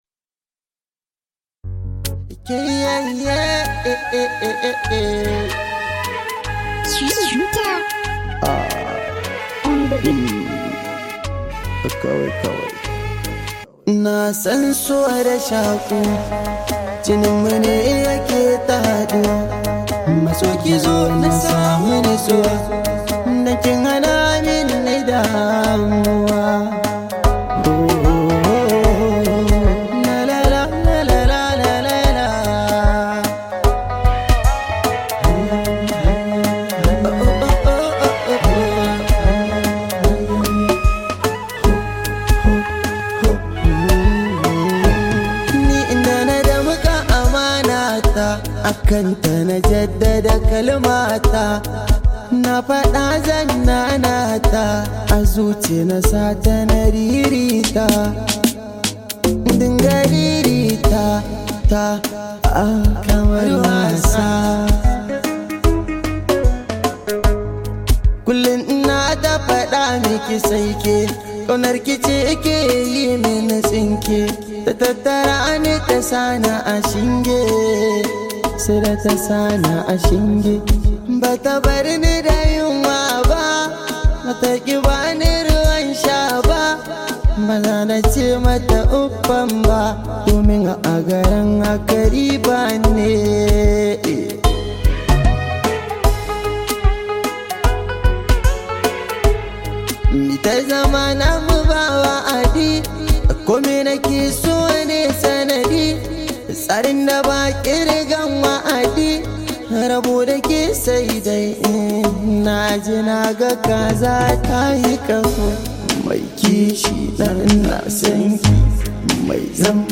Category: Hausa Songs
a romantic sorrow song.